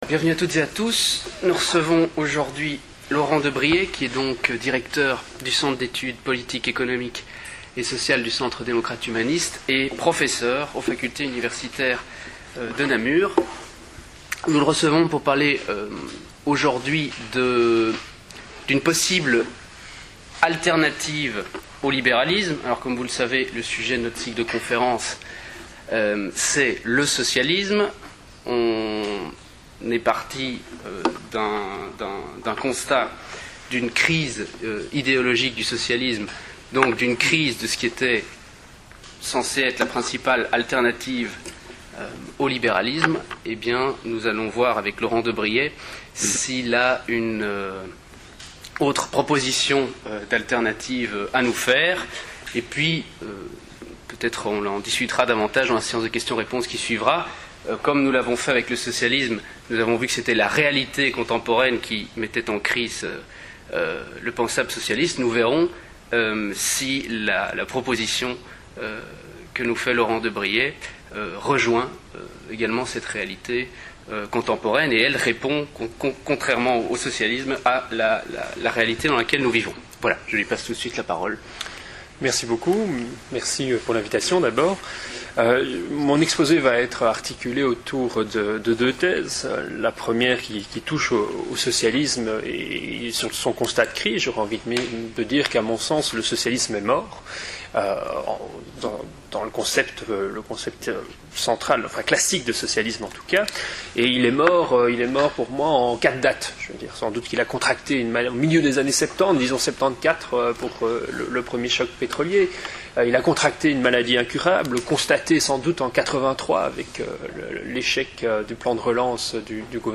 Conférences au format mp3